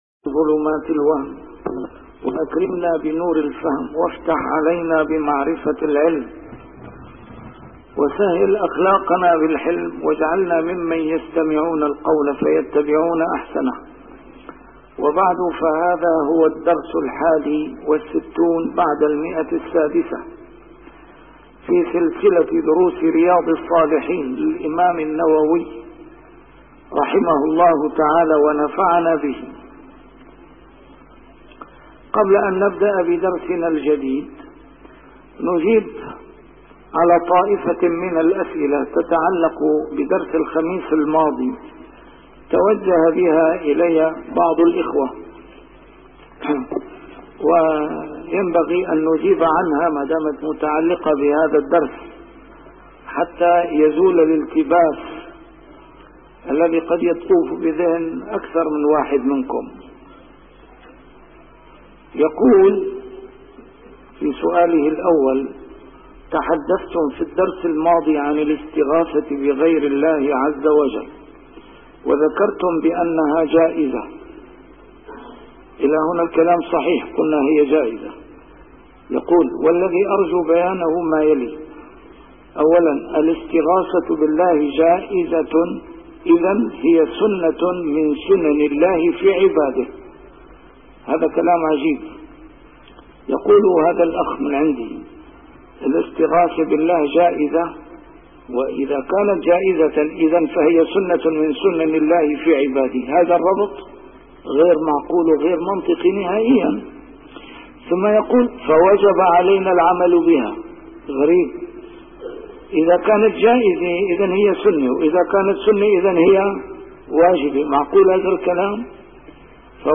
A MARTYR SCHOLAR: IMAM MUHAMMAD SAEED RAMADAN AL-BOUTI - الدروس العلمية - شرح كتاب رياض الصالحين - 661- شرح رياض الصالحين: صفة طول القميص